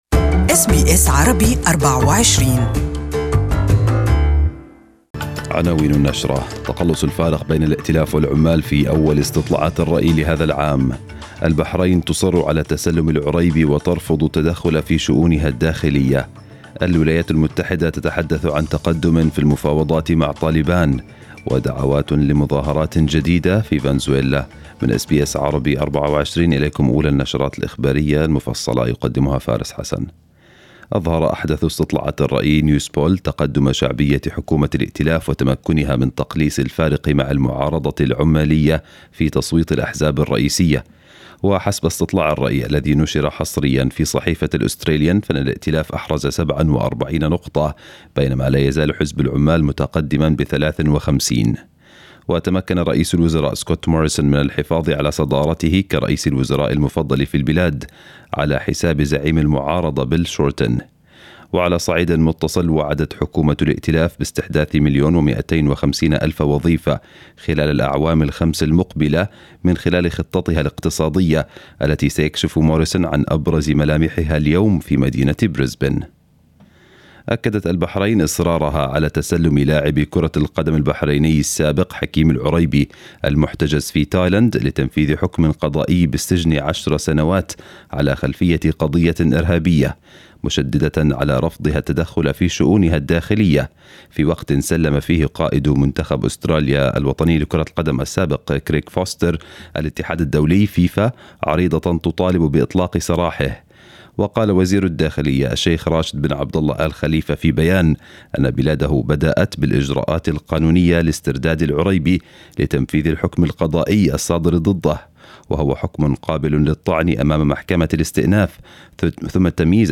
News Bulletin in Arabic for this morning